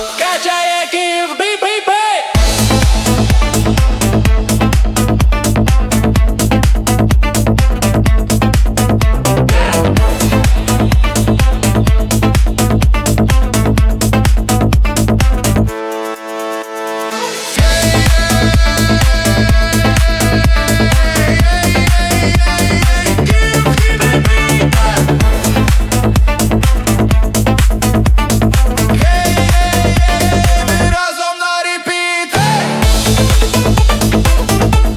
Жанр: Танцевальные / Украинские
Dance, Fitness & Workout